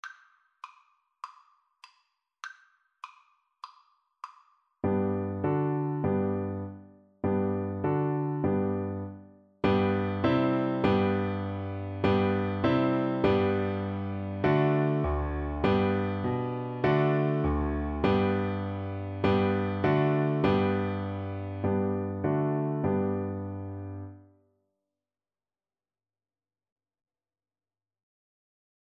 Play (or use space bar on your keyboard) Pause Music Playalong - Piano Accompaniment Playalong Band Accompaniment not yet available transpose reset tempo print settings full screen
Traditional Music of unknown author.
4/4 (View more 4/4 Music)
G major (Sounding Pitch) (View more G major Music for Cello )